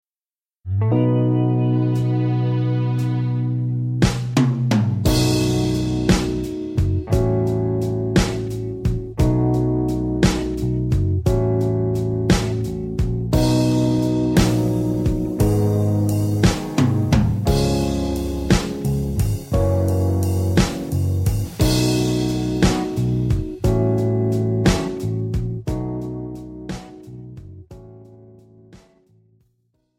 Pop , Rock , Soul